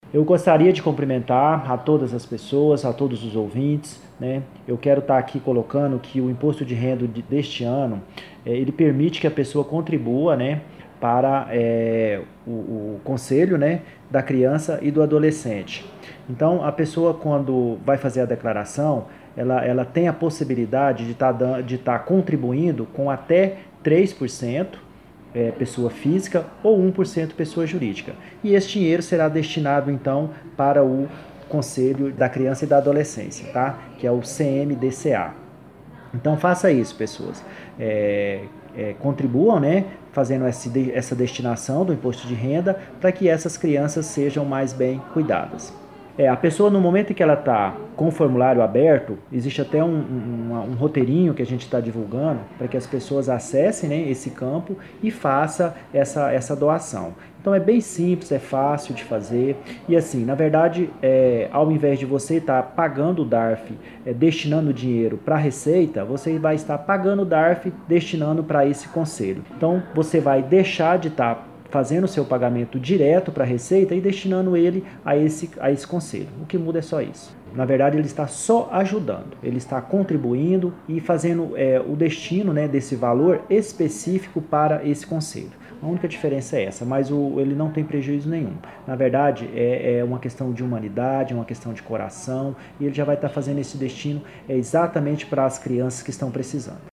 Acesse nosso podcast e ouça a Sonora do secretário de Administração e Finanças do Município, Divino Gustavo Carias, explica como funciona a destinação de parte do imposto de renda de pessoas físicas e jurídicas para o Conselho da Criança e do Adolescente.